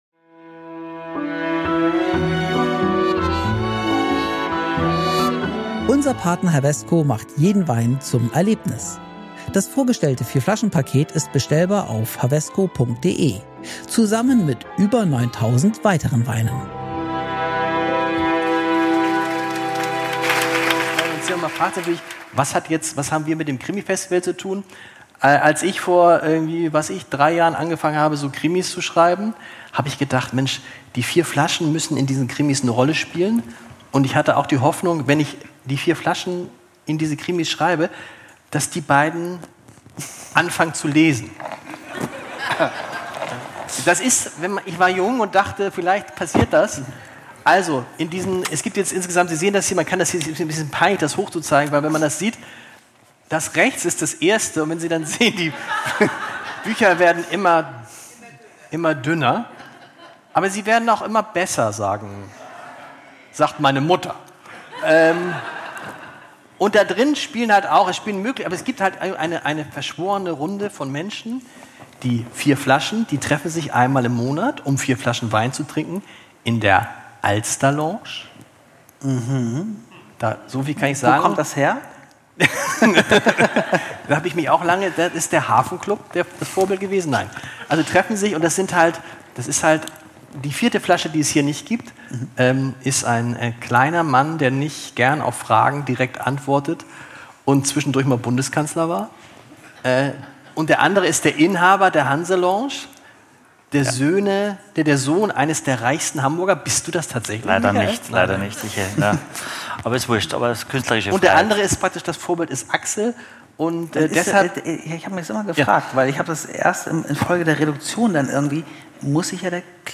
Diese Folge haben wir beim 18. Hamburger Krimifestival auf Kampnagel aufgenommen. Bei uns waren knapp 100 Spürnasen, die lieber in Weingläsern als in Krimis stecken. Thema des Abends: Kriminell gefährliche Weine vom "Saboteur" bis zu 19. Crimes Weins aus Australien.